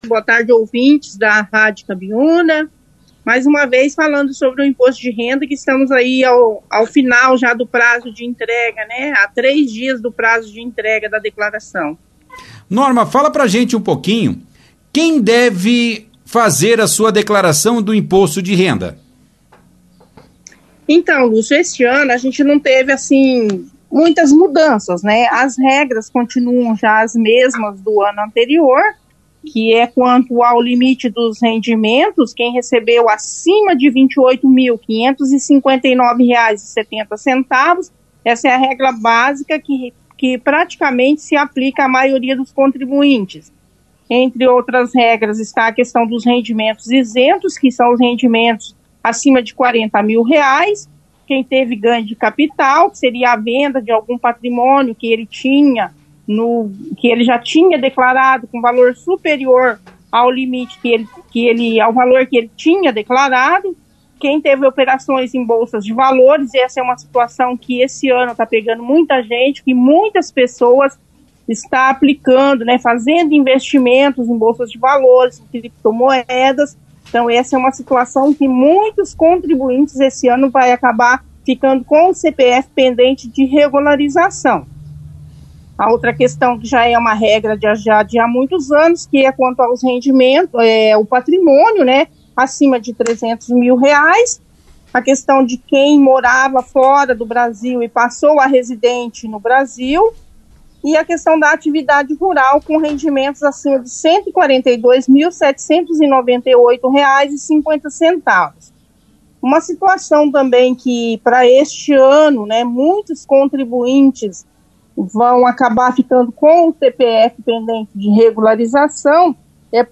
participou da edição deste sábado, 29/05, do jornal Operação Cidade